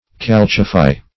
Calcify \Cal"ci*fy\ (k[a^]l"s[i^]*f[imac]), v. t. [imp.